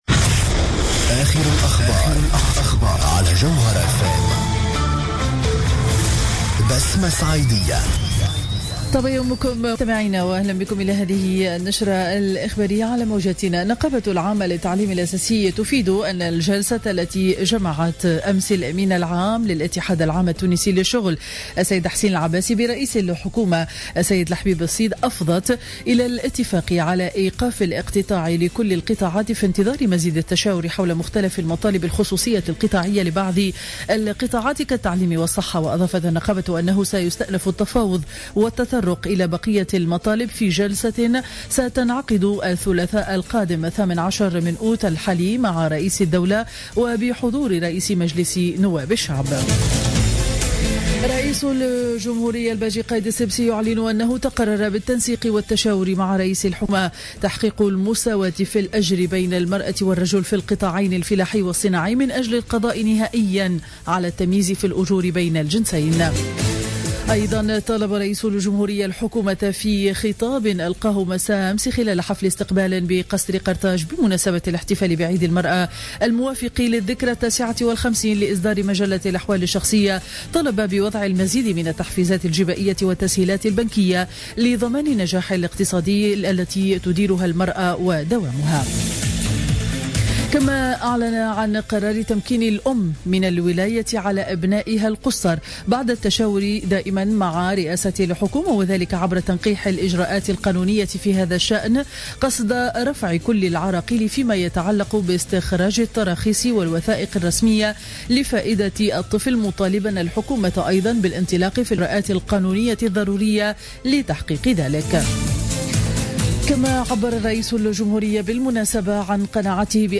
نشرة أخبار السابعة صباحا ليوم الجمعة 14 أوت 2015